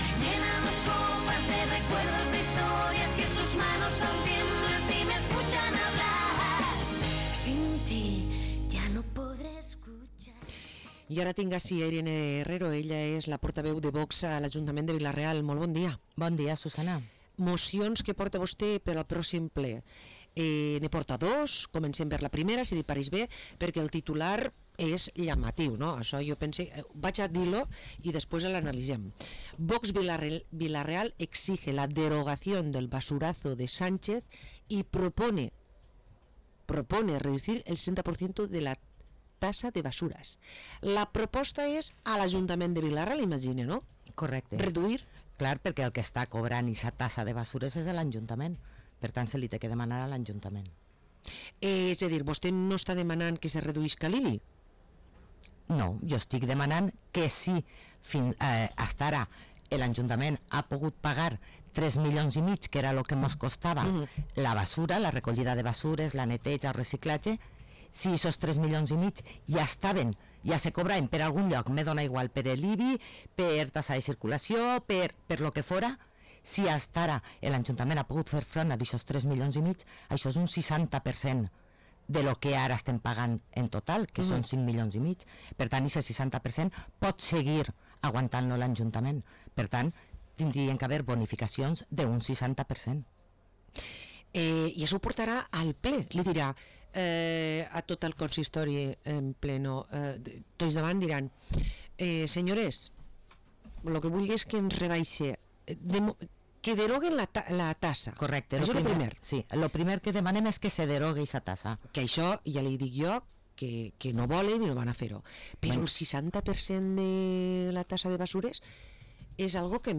Parlem amb la portaveu de VOX a l´Ajuntament de Vila-real, Irene Herrero